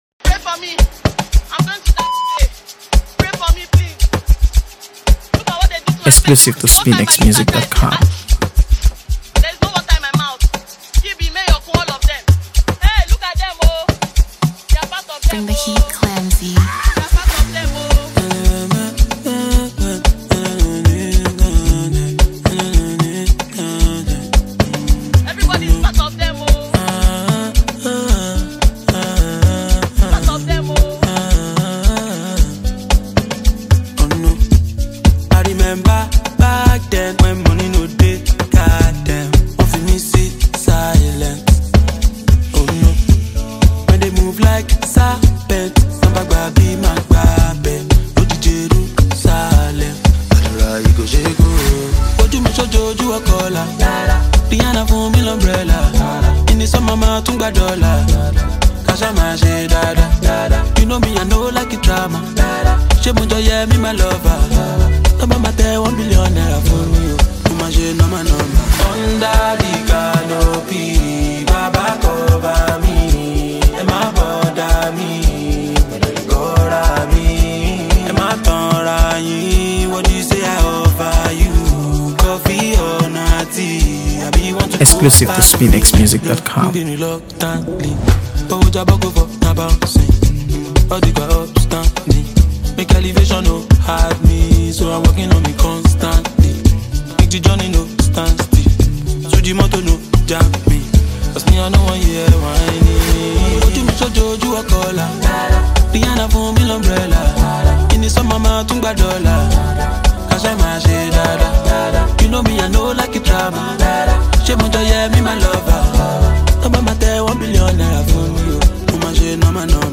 AfroBeats | AfroBeats songs
infectious rhythm and catchy hooks